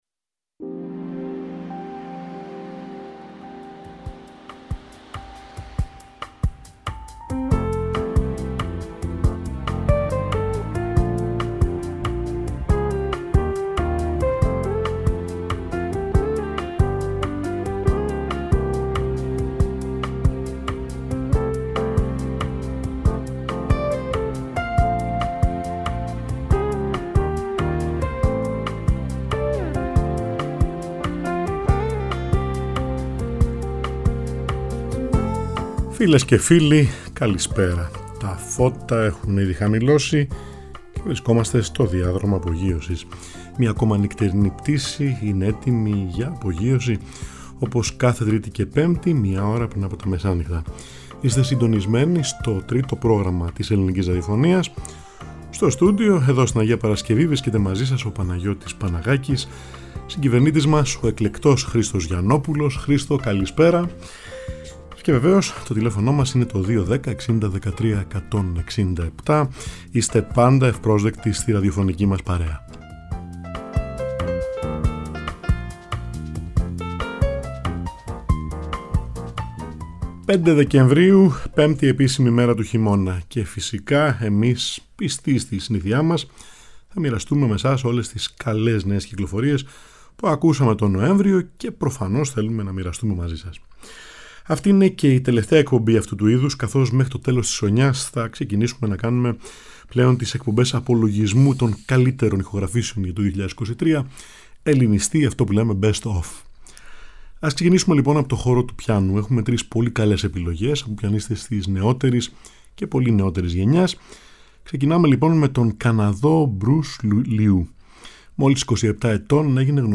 τραγούδια από την Αργεντινή
που αποτελείται από τρομπέτα, πιάνο και μπάσο
μαντολίνο